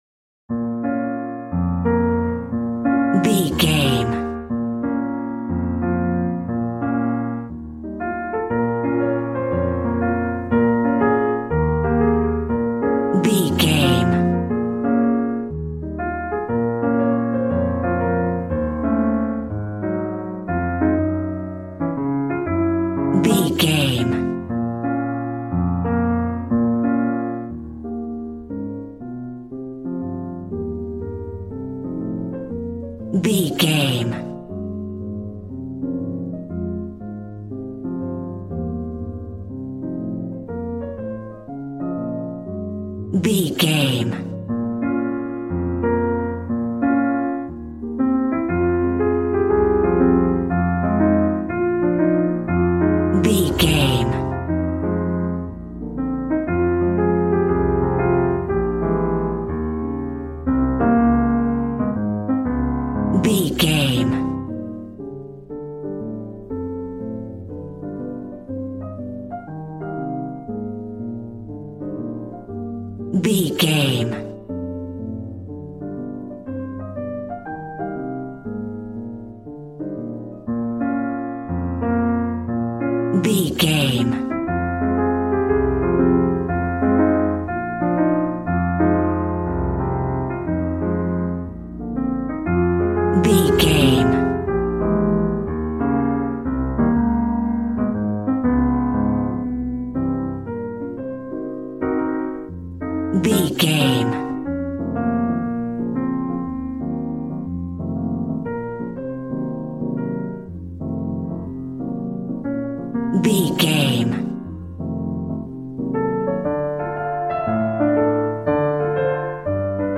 Smooth jazz piano mixed with jazz bass and cool jazz drums.,
Ionian/Major
piano